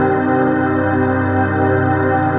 Amb Space.wav